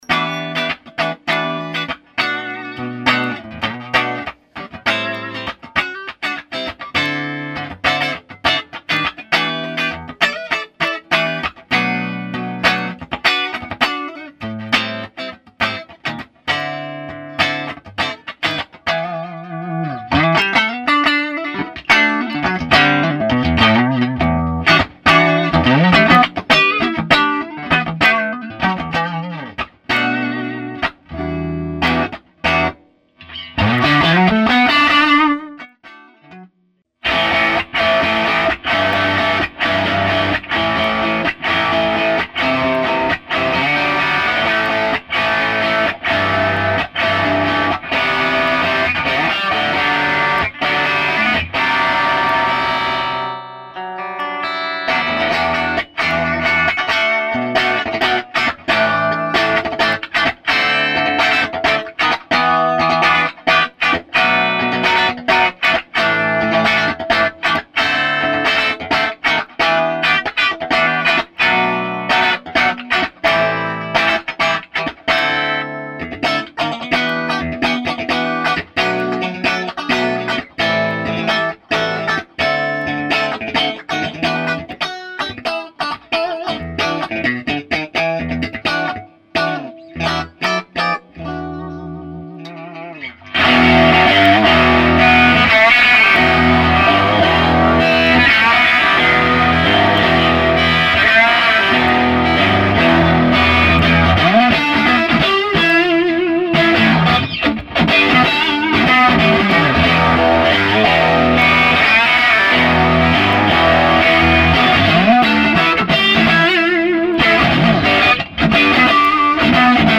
kond. Geminy v priestore, cca 2m od boxu a dost vysoko
Celkova hlasitost aparatu bola naplno!
Ukazky su bez pouzitia efektov a EQ, iba priamy signal z mikrofonov.